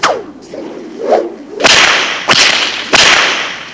tailwhip.wav